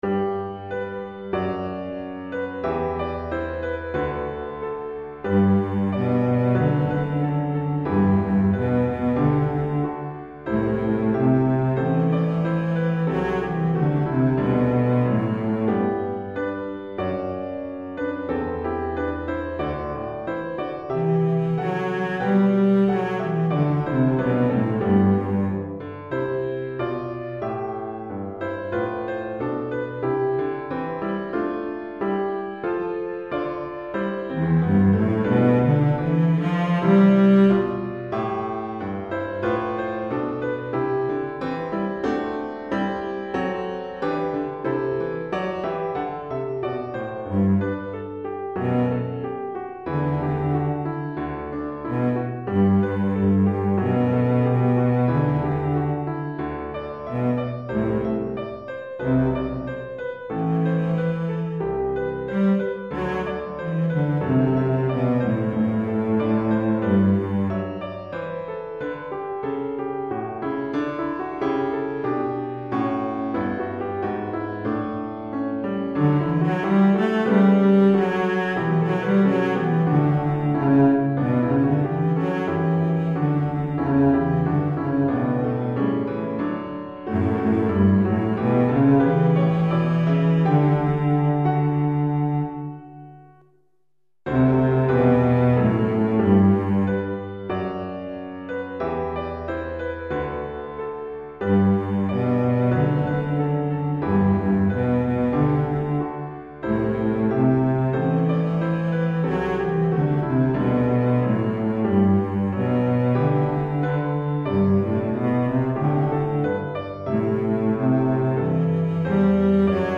Violoncelle et Piano